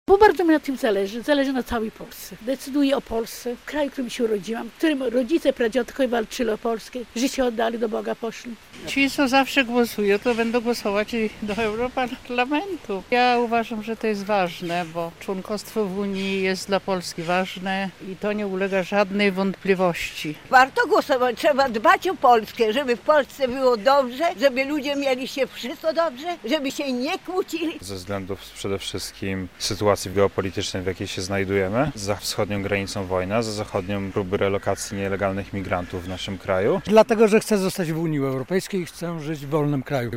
Głosujący mieszkańcy Łomży uważają pójście na wybory za swój obowiązek - relacja